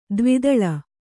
♪ dvidaḷa